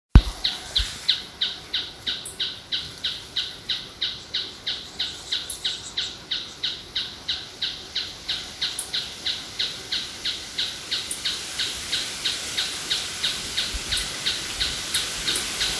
Churrín Plomizo (Scytalopus pachecoi)
Nombre en inglés: Planalto Tapaculo
Localidad o área protegida: Parque Provincial Caá Yarí
Condición: Silvestre
Certeza: Vocalización Grabada
Churrin-plomizo-Caa-Yari.mp3